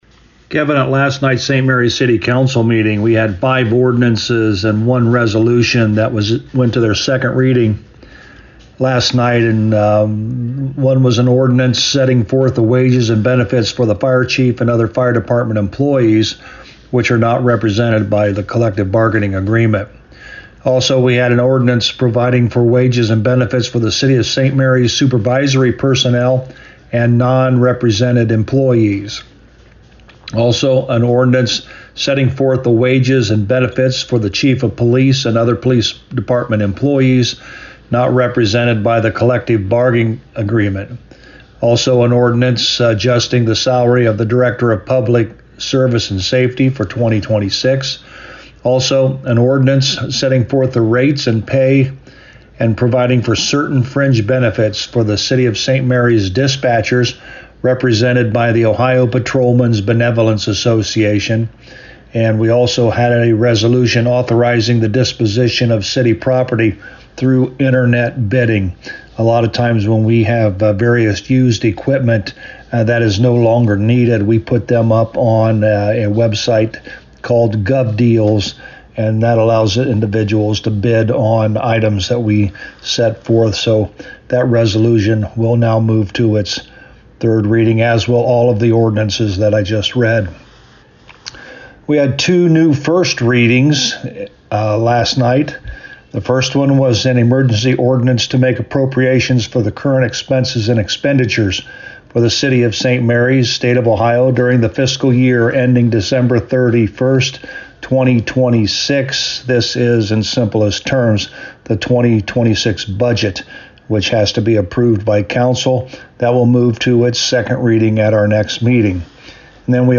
To hear Mayor Hurlburt's report: